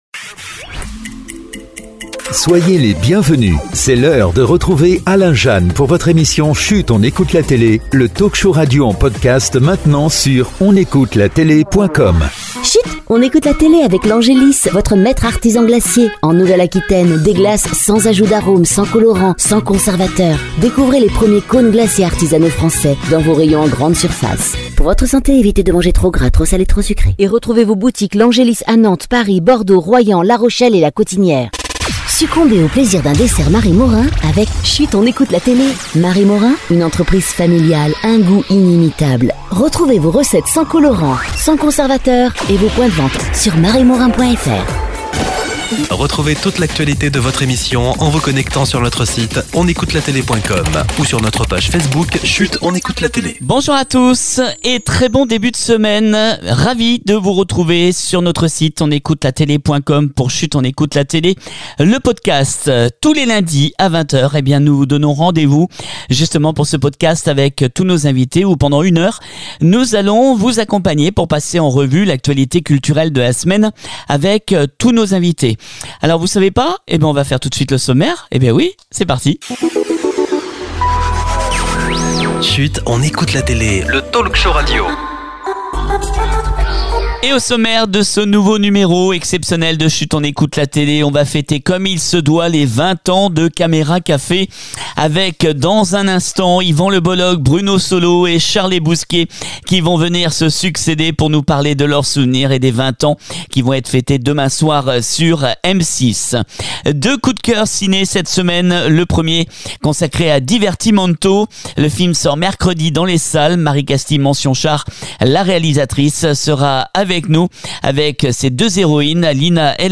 nos coups de coeur télé consacré à “piste noire” qui débarque sur France 2 avec Thibaut de Montalembert qui est en ligne avec nous, l’histoire d’Annette Zelman à l’honneur avec Ilona Bachelier et Vassili Schneider sans oublier en exclusivité podcast le réalisateur Philippe le Guay qui sont avec nous